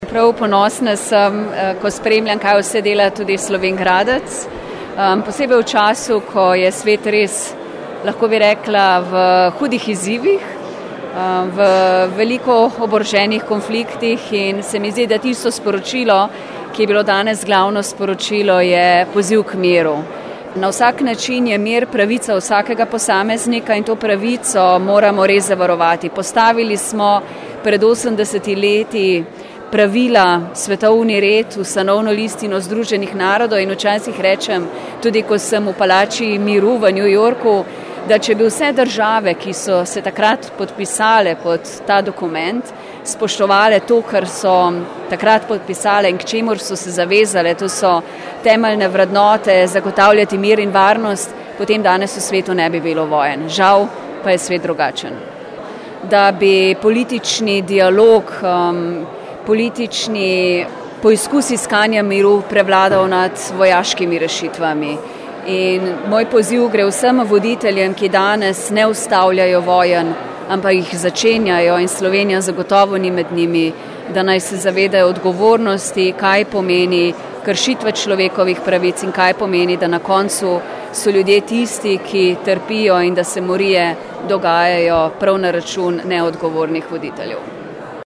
slavnostna govornica je bila ministrica za evropske in zunanje zadeve RS Tanja Fajon:
IZJAVA TANJA FAJON .MP3